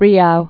(rēou)